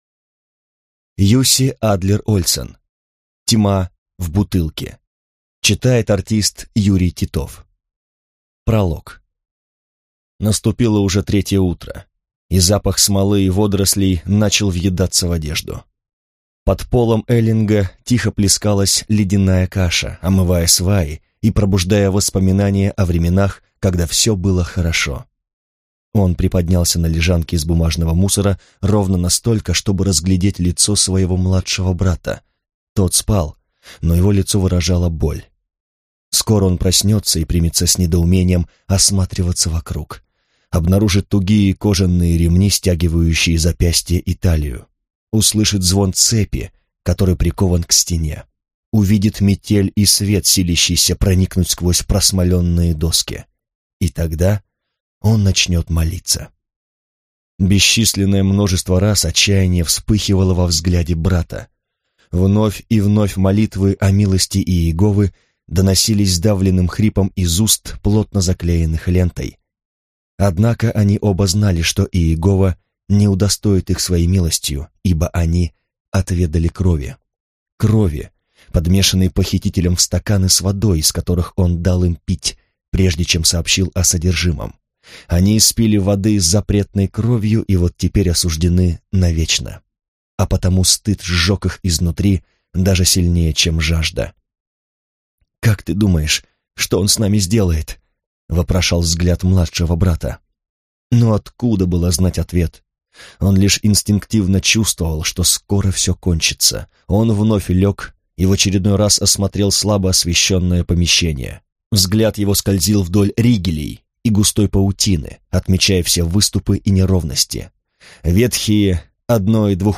Аудиокнига Тьма в бутылке | Библиотека аудиокниг